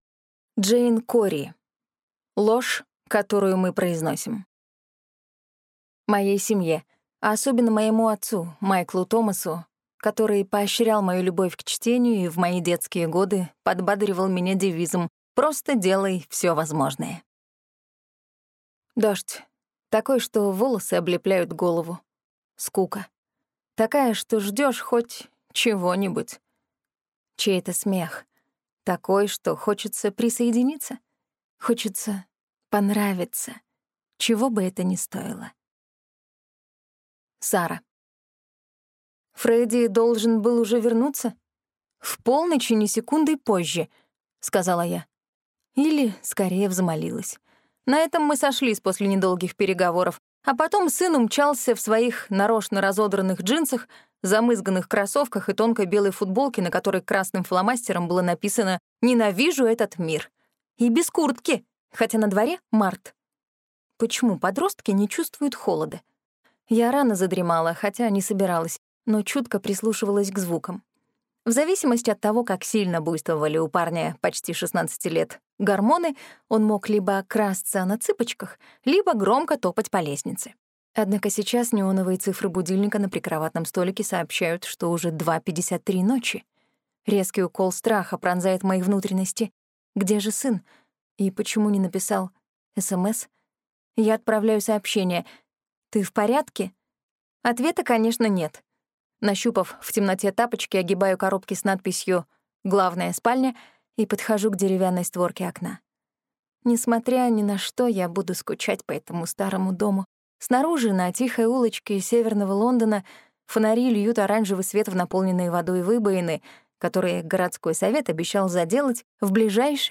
Аудиокнига Ложь, которую мы произносим | Библиотека аудиокниг